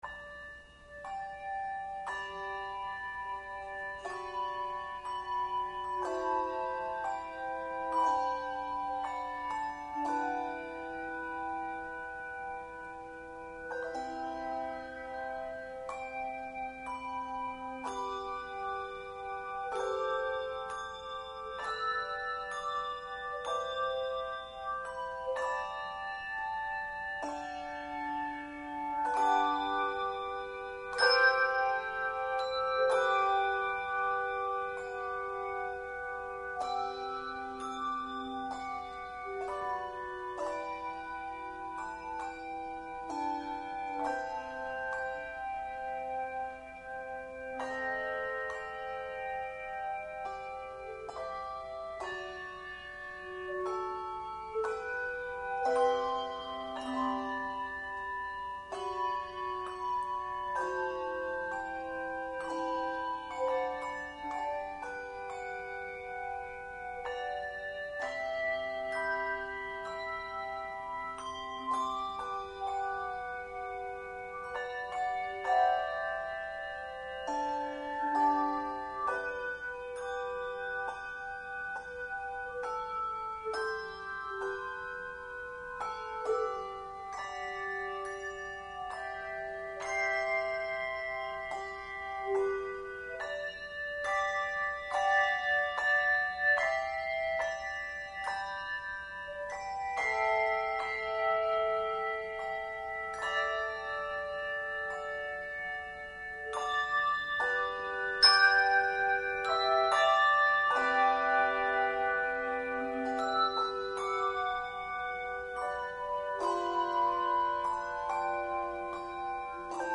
Handbell Quartet
Genre Sacred
No. Octaves 3 Octaves